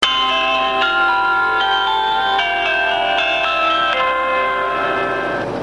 チャイム４
チャイム１は発車後、チャイム２は出発地側最後の停留所発車後、チャイム３は到着前、チャイム４は終着で流れます。